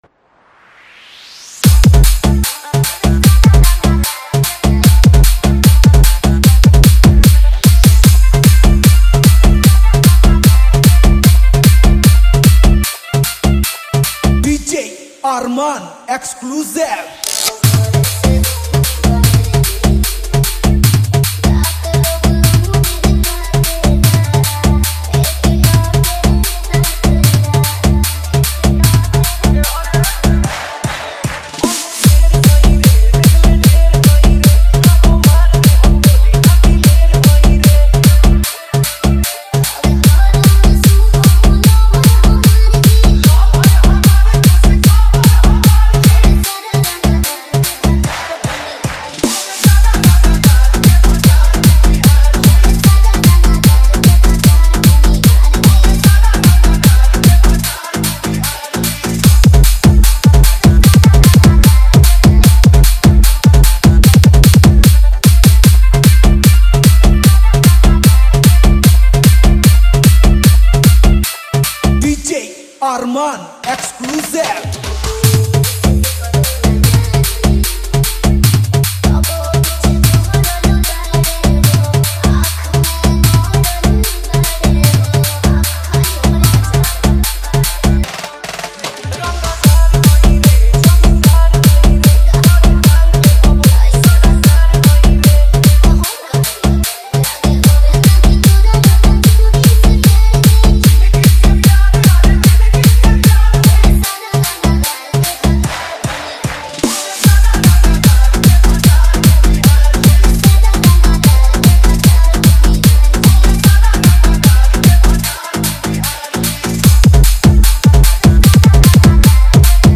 Category : Bhojpuri Remix Song